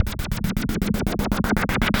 Index of /musicradar/rhythmic-inspiration-samples/120bpm
RI_RhythNoise_120-03.wav